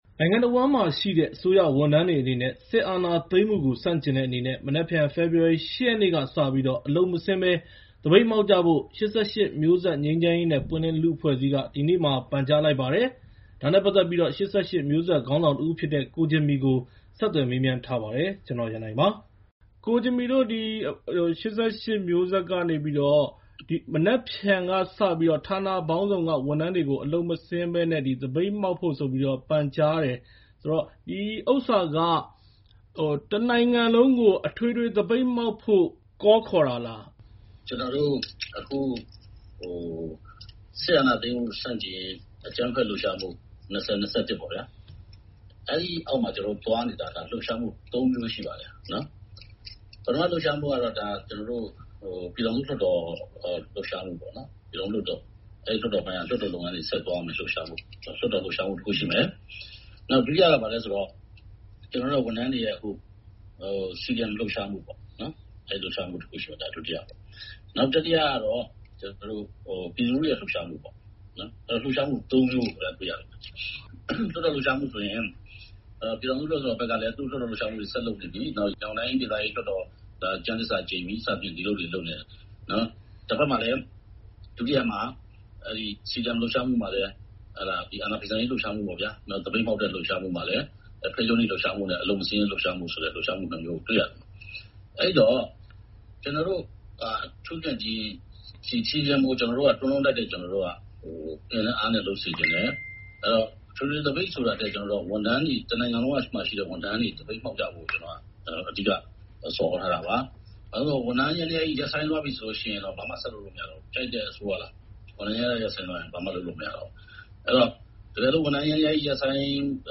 ၈၈ ငြိမ်း/ပွင့်ခေါင်းဆောင် ကိုဂျင်မီနဲ့ VOA ဆက်သွယ်မေးမြန်းချက်